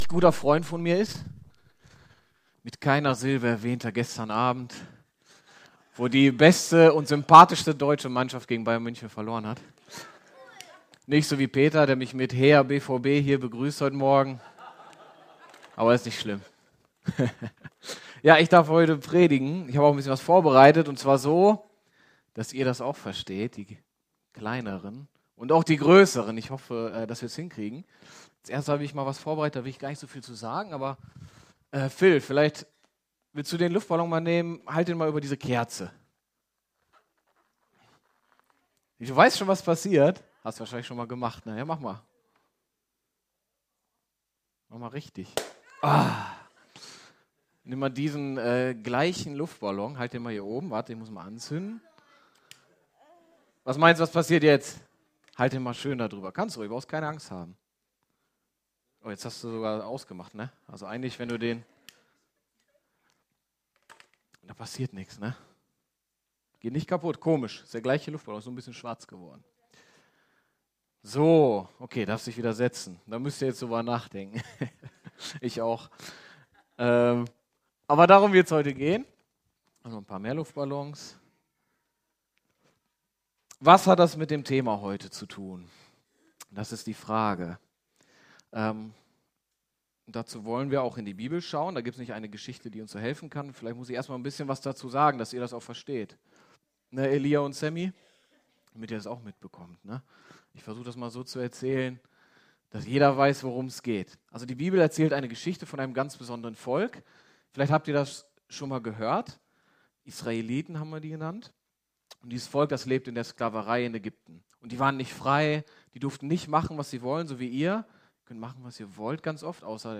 Predigt vom 7. April 2019 – efg Lage
Gottesdienst